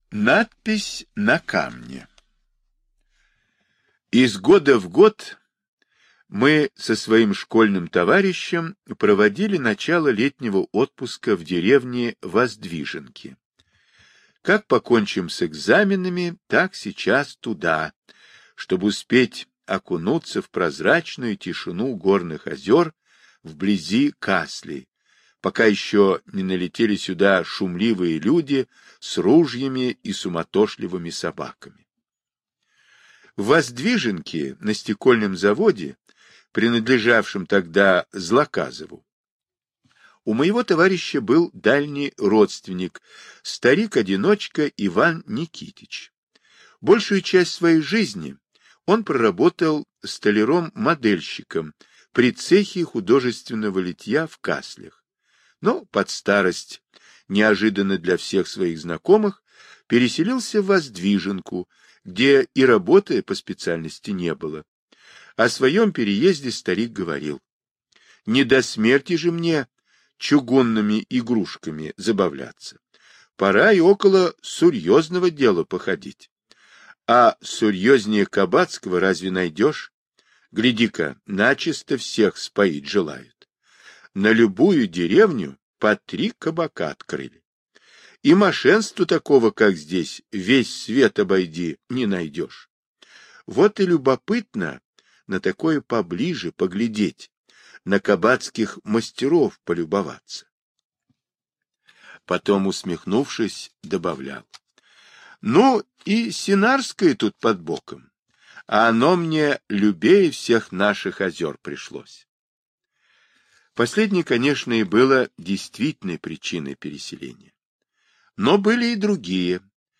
Надпись на камне - аудиосказка Павла Бажова - слушать онлайн